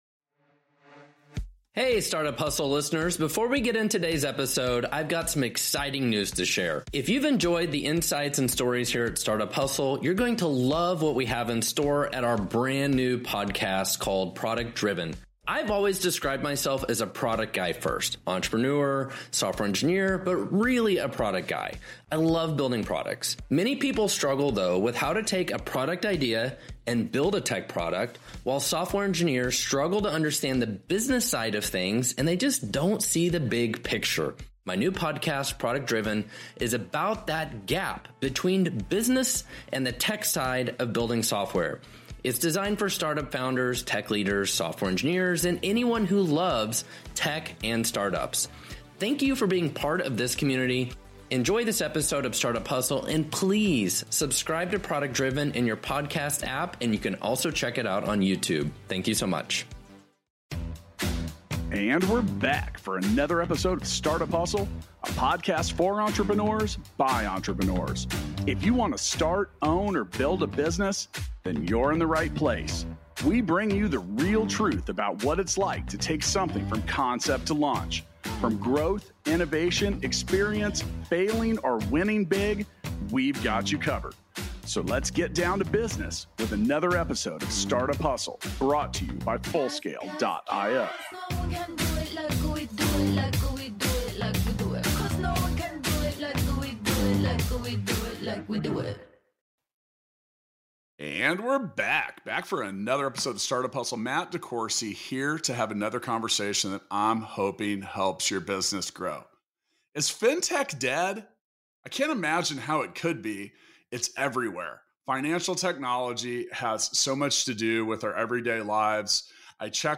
enjoy a very fluid discussion together covering a wide variety of Fintech topics. So, is Fintech dead, and should startup founders start throwing in the towel?